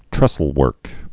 (trĕsəl-wûrk)